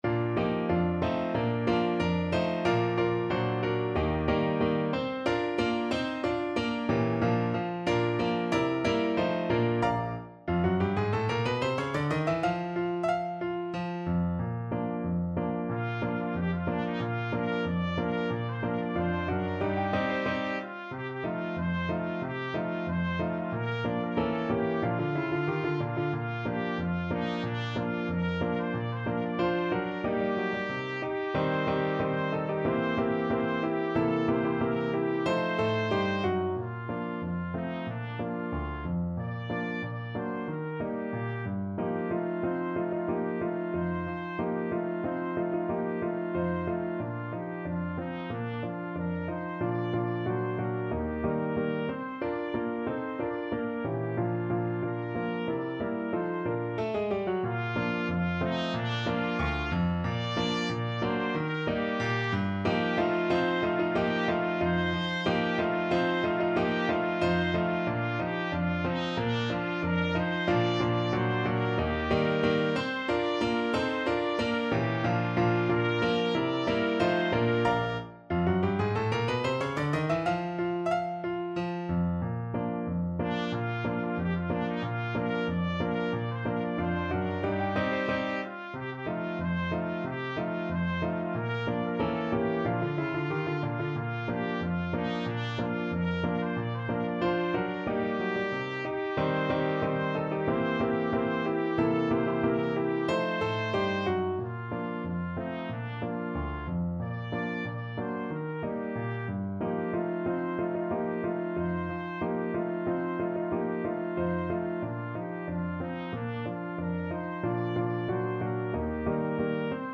2/4 (View more 2/4 Music)
Allegretto =92
Traditional (View more Traditional Trumpet Music)